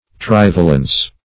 Meaning of trivalence. trivalence synonyms, pronunciation, spelling and more from Free Dictionary.
trivalence - definition of trivalence - synonyms, pronunciation, spelling from Free Dictionary Search Result for " trivalence" : The Collaborative International Dictionary of English v.0.48: Trivalence \Triv"a*lence\, n. (Chem.)